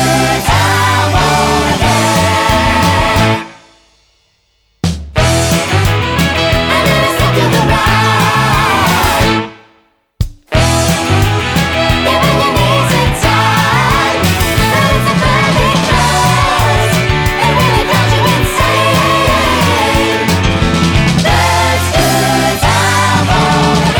no Backing Vocals Soundtracks 3:11 Buy £1.50